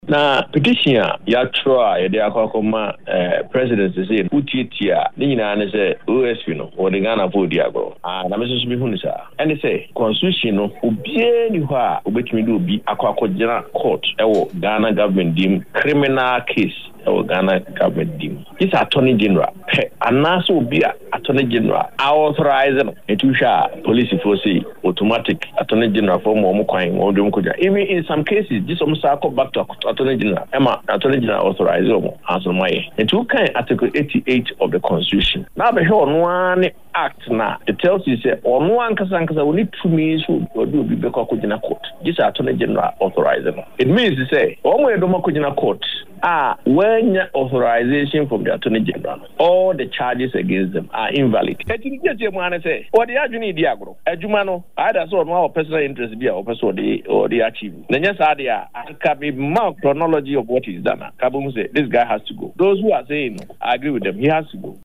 In an interview
on Ahotor FM